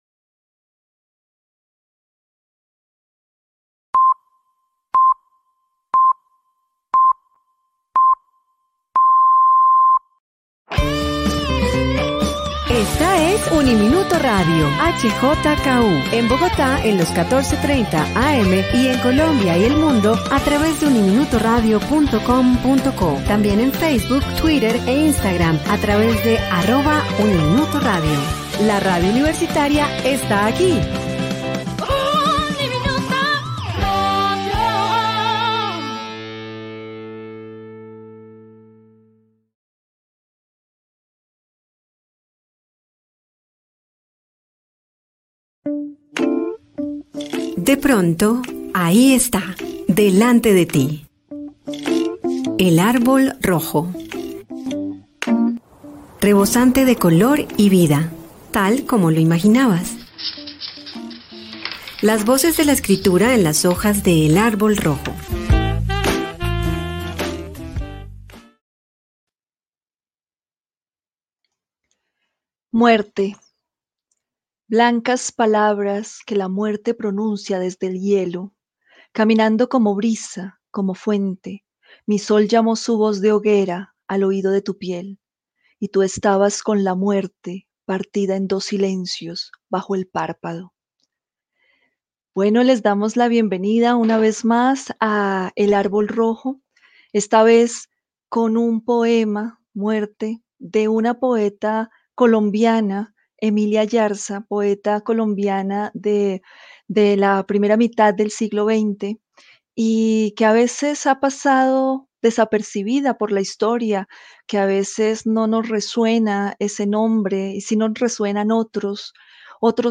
Aquí pueden escuchar el programa completo